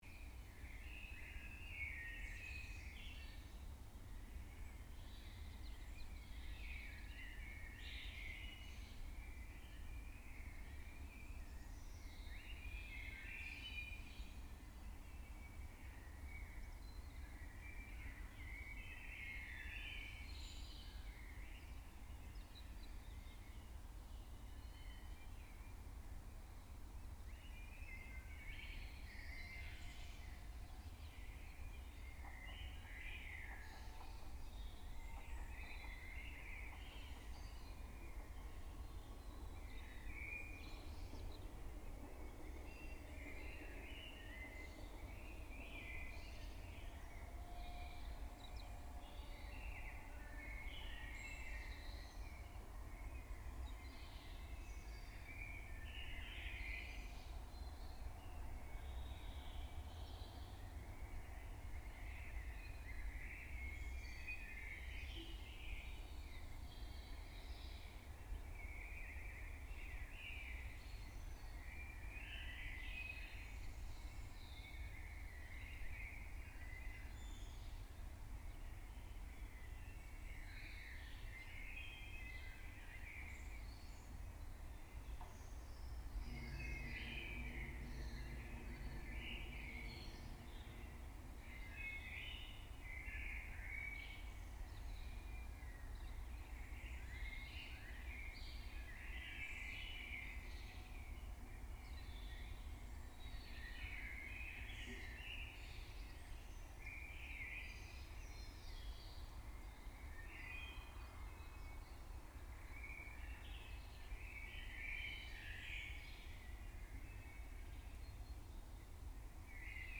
Touch Radio 94 | Urban Dawn Chorus
Recorded in Balham, south west London, from 0400 HRS 1st May 2013